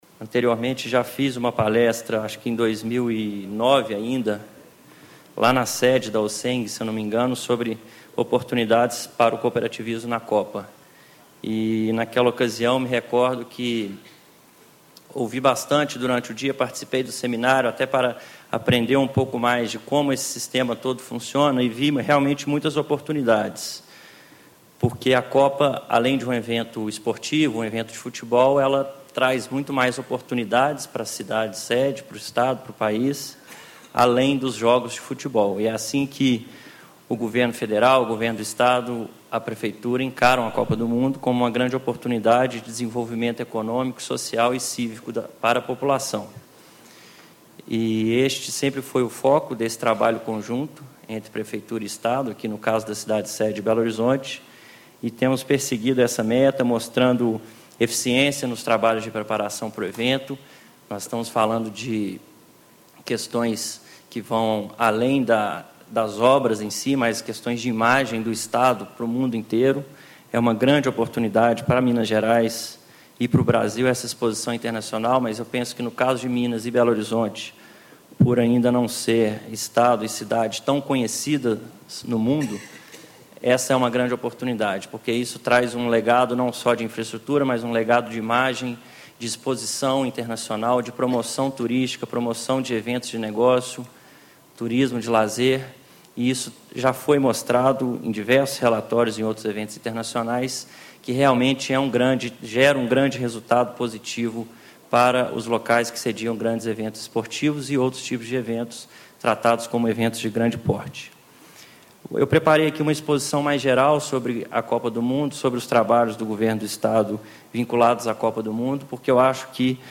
Tiago Lacerda, Secretário Extraordinário da Copa do Mundo. Painel: Oportunidades de negócios para o cooperativismo nas Copas das Confederações e do Mundo e nas Olimpíadas
Discursos e Palestras